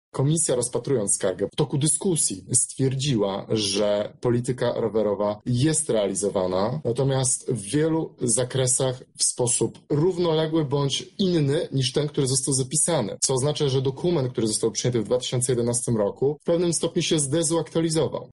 Już na etapie pracy w komisji uznaliśmy, że skarga jest bezzasadna – tłumaczy jej przewodniczący, radny Marcin Bubicz: